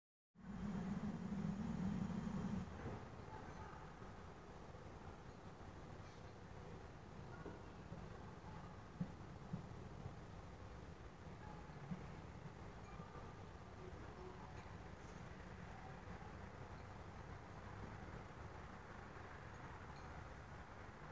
Sikspārnis (nenoteikts), Vespertilionidae sp.
Ziņotāja saglabāts vietas nosaukumsPagalms
СтатусСлышен голос, крики
Mājas pagalmā jau kādu trešo vakaru lido dzīvnieks (iesp., sikspārnis) ar pārsteidzoši dzirdamu (un nedaudz kaitinošu) saucienu. Ierakstot pagalma skaņas telefonā, un atverot audio failu (pievienots šeit) kā spektrogrammu Sonic Visualiser programmā, konstatēju, ka dzīvnieka sauciens ir ap 12-15kHz diapozonā (pievienoju šeit kā attēlu).
Pēc skaņas amplitūdas izmaiņas (gan klausoties ar ausi, gan pēc spektrogrammas) šķiet, ka tas pārvietojas pa pagalmu.
Jāpiemin, ka ieraksts veikts ar telefona mikrofonu, tādēļ frekvences virs 20 kHz netiek ierakstītas.